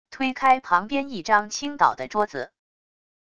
推开旁边一张倾倒的桌子wav音频